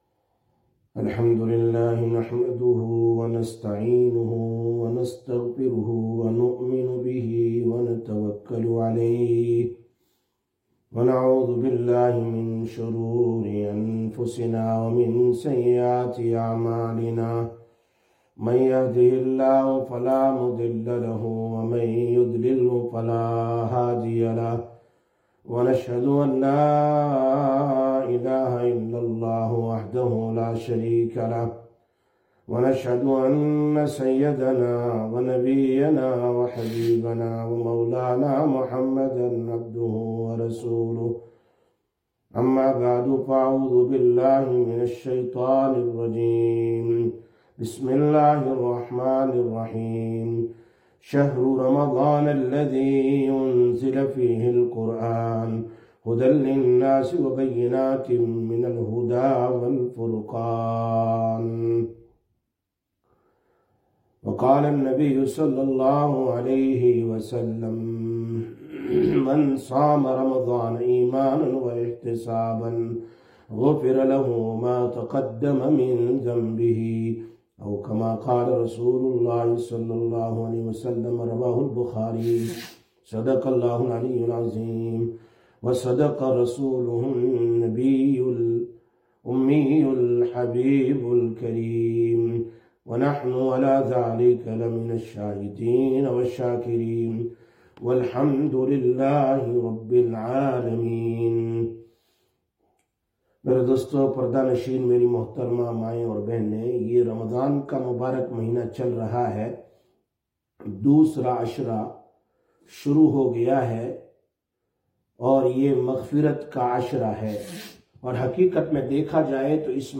12/03/2025 Sisters Bayan, Masjid Quba